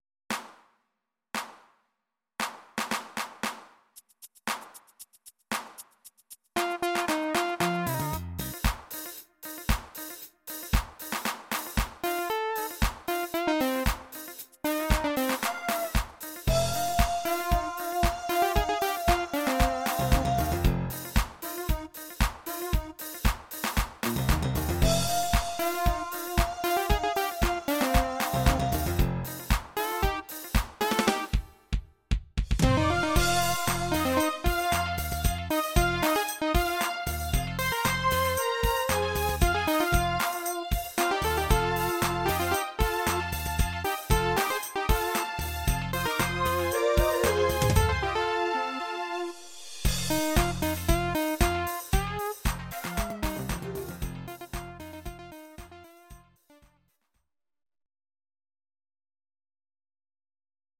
Fm
Audio Recordings based on Midi-files
Pop, Disco, Musical/Film/TV, 2000s